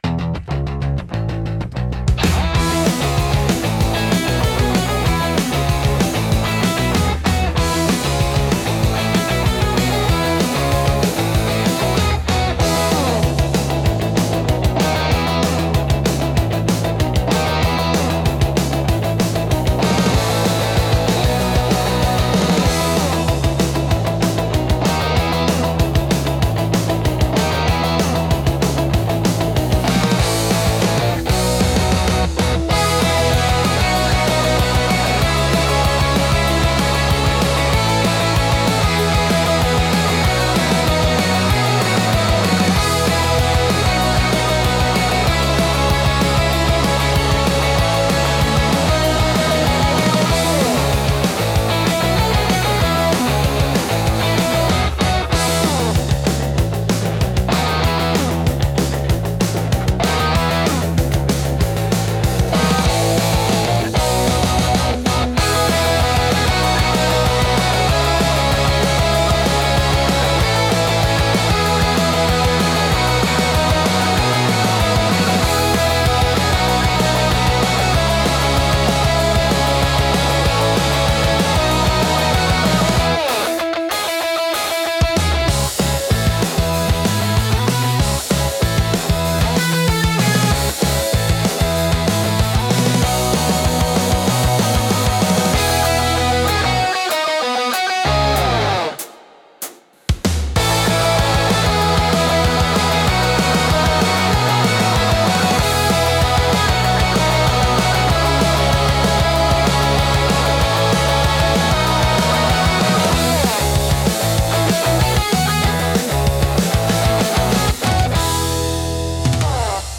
生々しさと疾走感を併せ持つ力強いジャンルです。